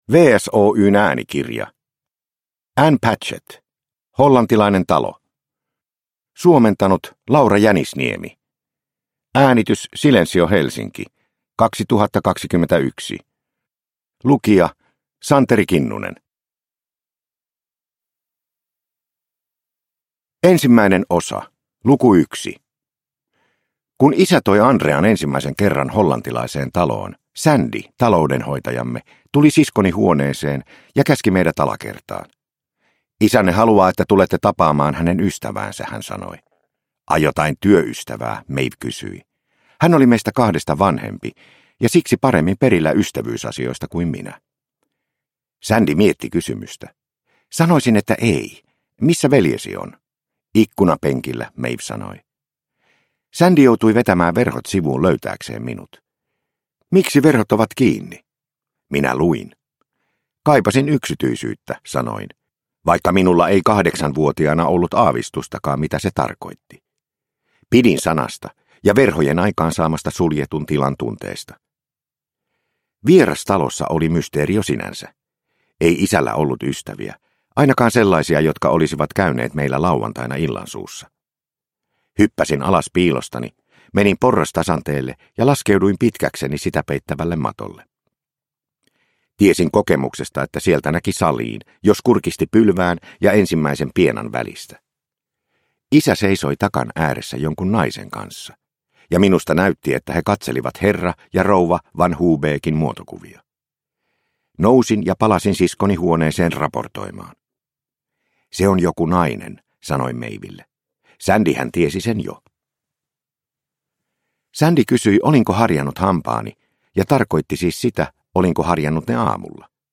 Hollantilainen talo – Ljudbok – Laddas ner